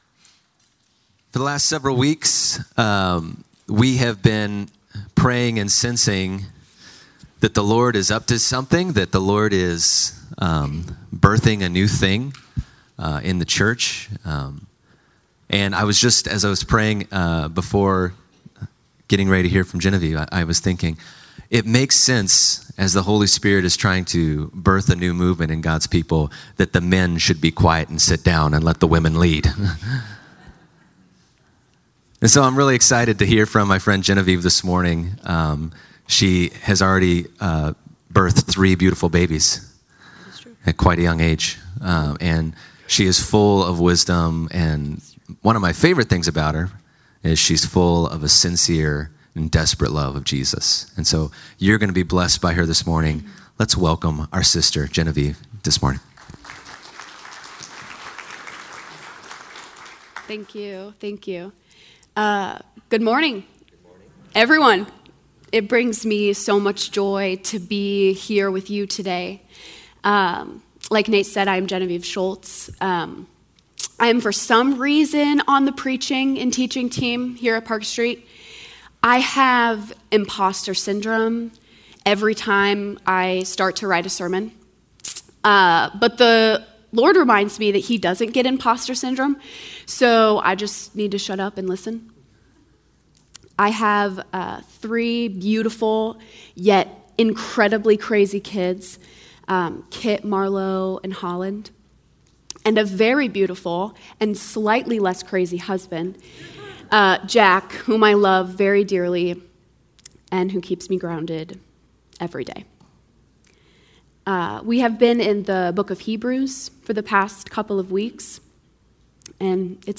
Sermons - Park Street Brethren Church
Sermon Series